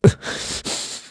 Clause_ice-Vox_Sad_kr.wav